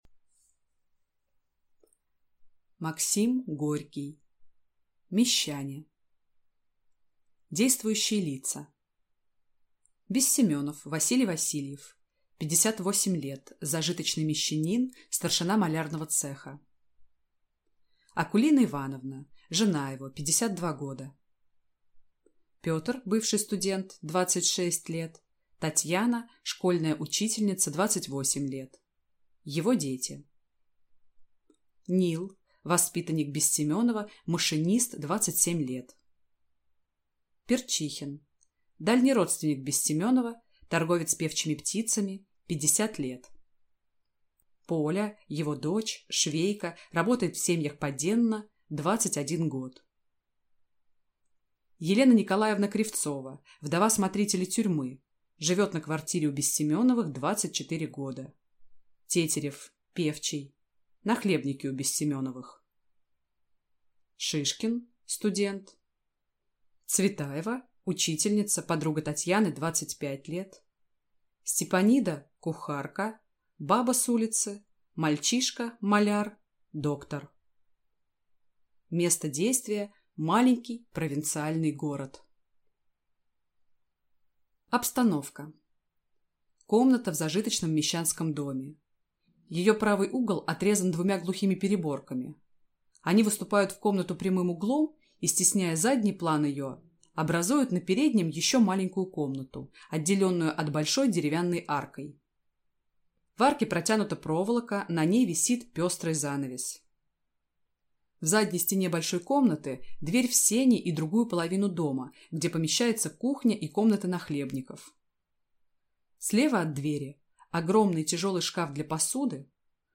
Аудиокнига Мещане | Библиотека аудиокниг